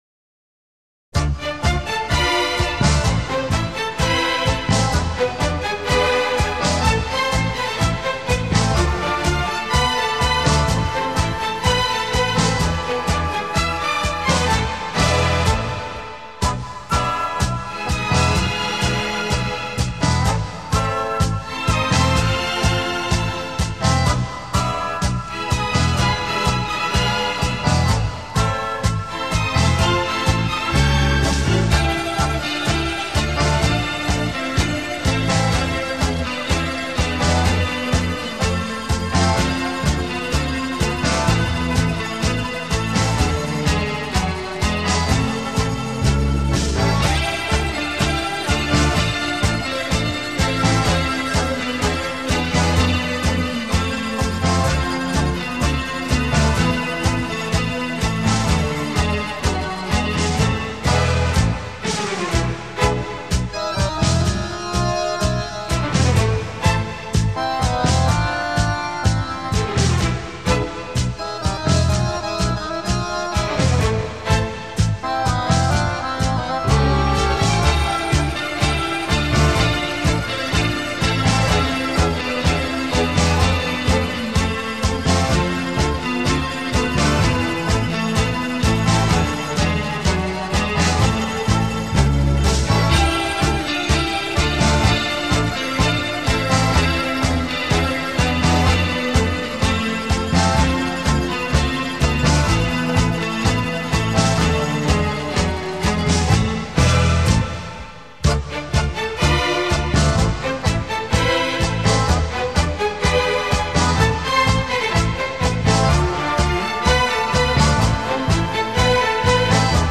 Ballroom Dance